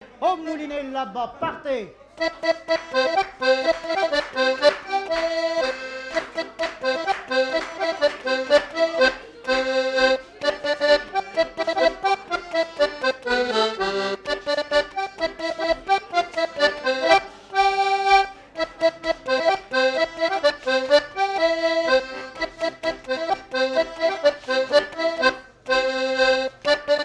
danse : quadrille : moulinet
Fête de l'accordéon
Pièce musicale inédite